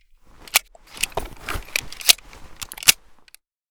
pm_reload_empty.ogg